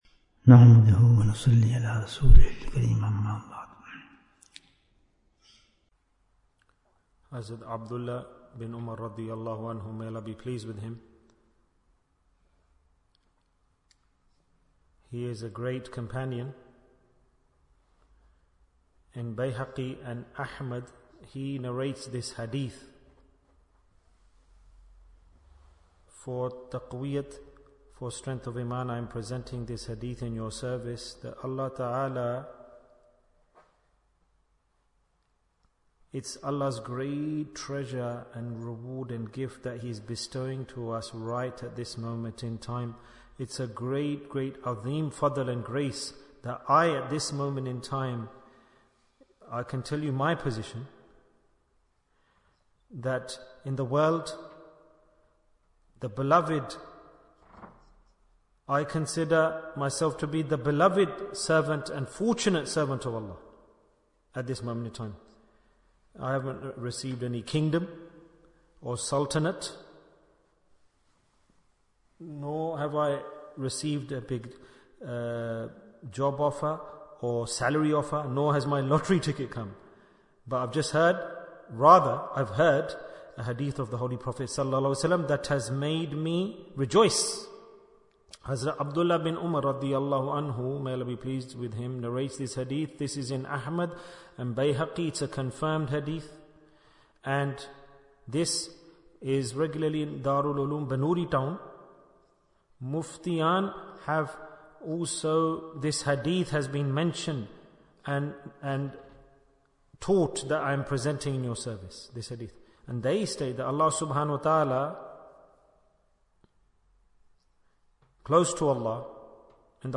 Dhikr in Dhul Hijjah Bayan, 11 minutes7th June, 2024